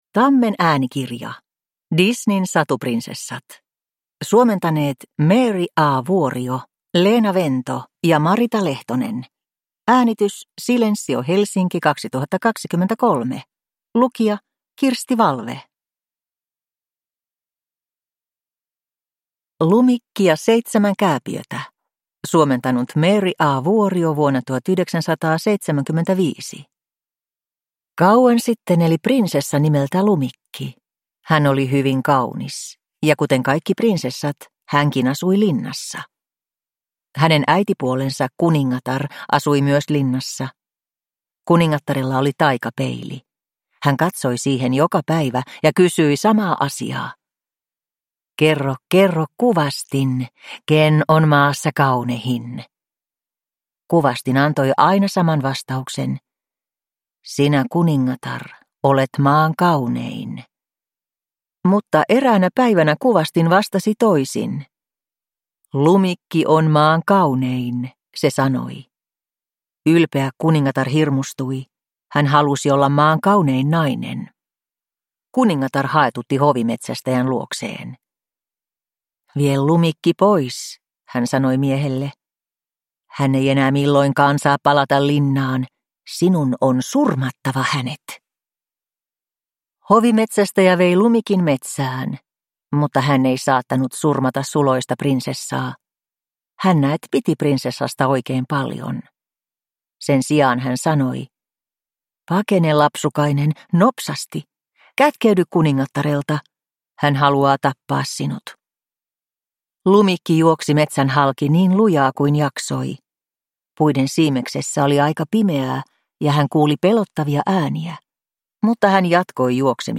Disneyn satuprinsessat – Ljudbok